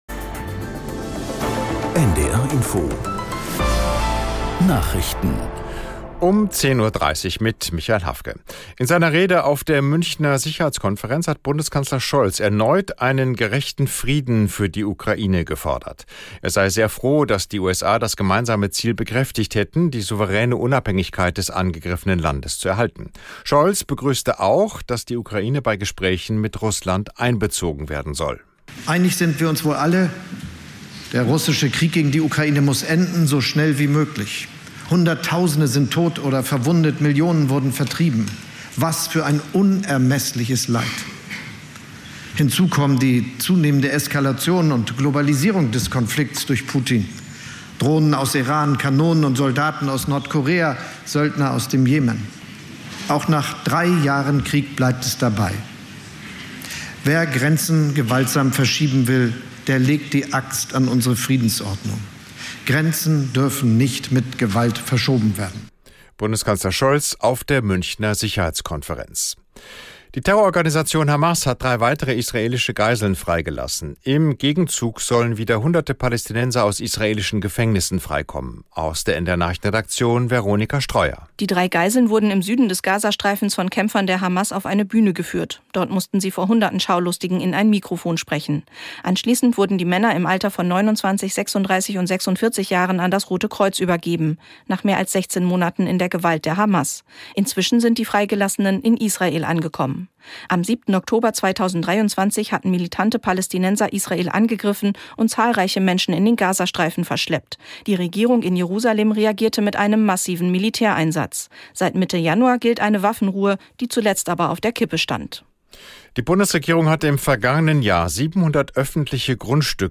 Nachrichten - 15.02.2025